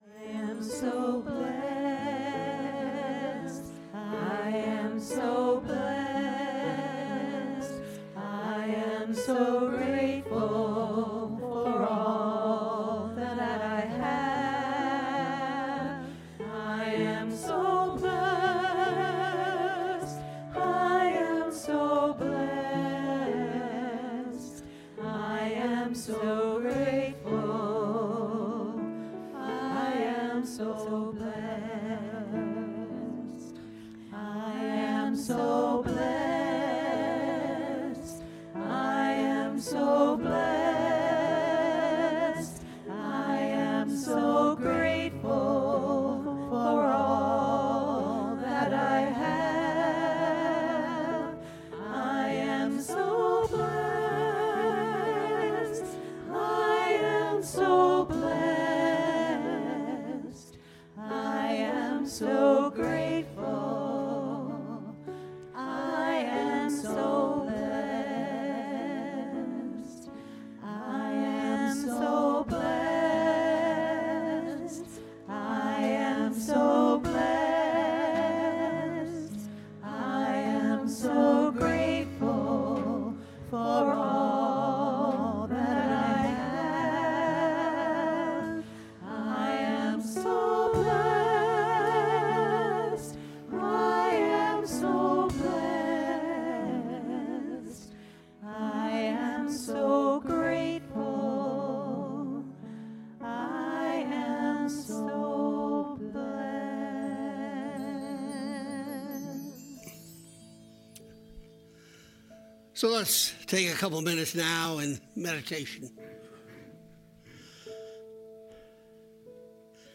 The audio recording (below the video clip) is an abbreviation of the service. It includes the Meditation, Lesson and Featured Song.